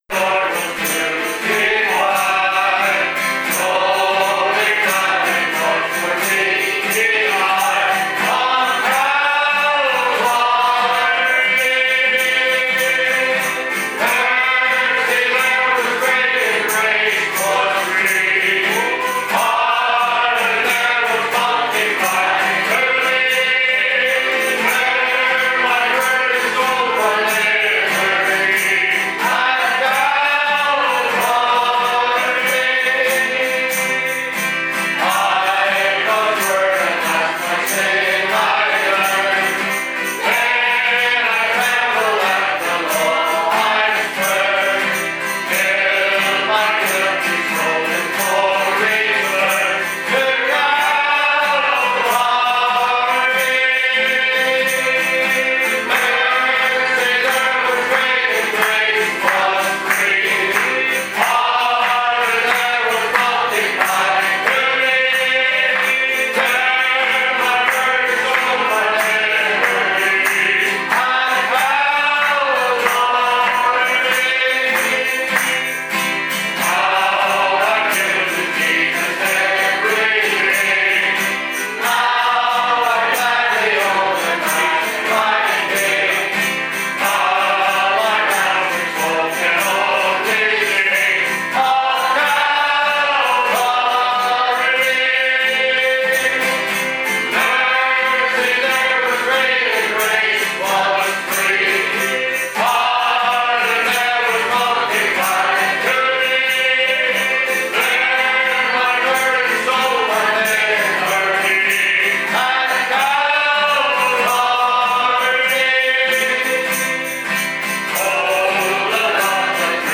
Omega Ministry - Audio Sermons